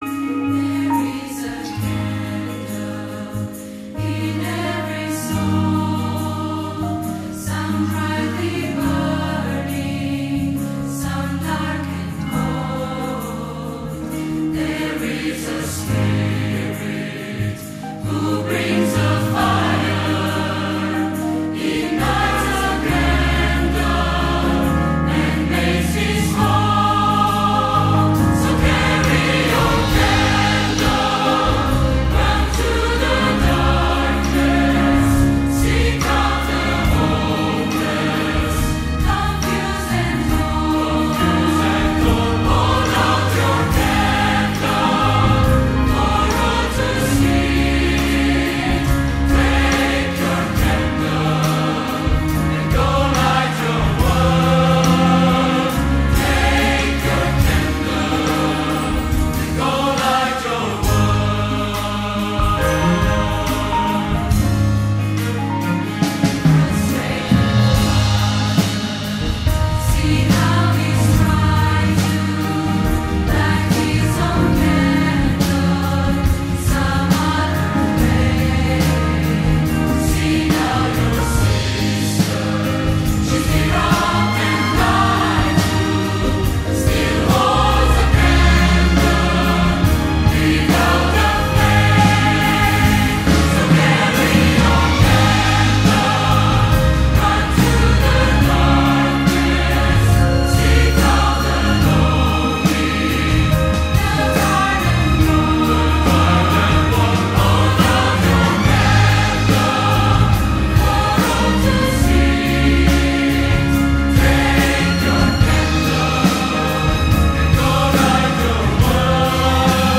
contemporary Christian song